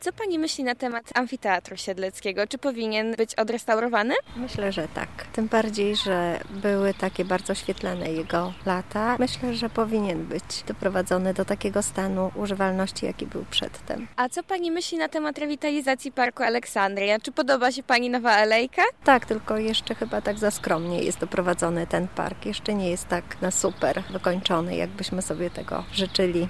amfiteatr_sonda.mp3